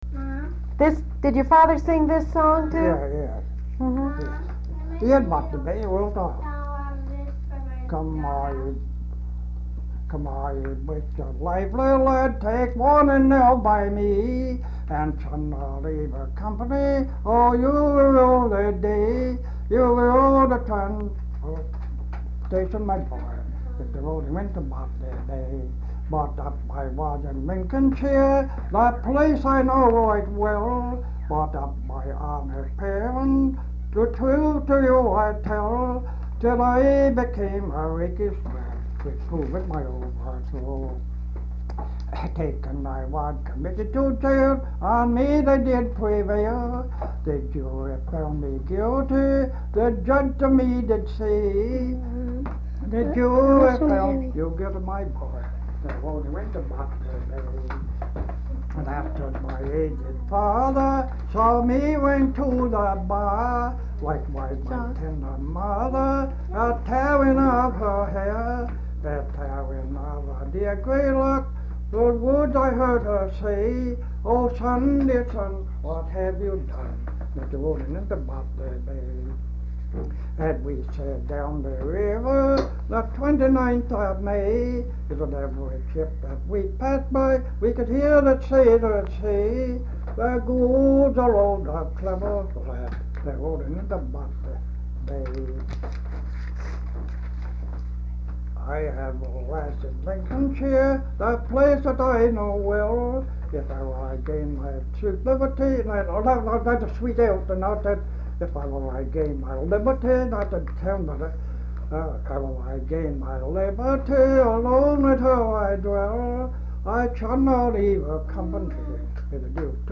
folksongs
Folk songs, English--Vermont
sound tape reel (analog)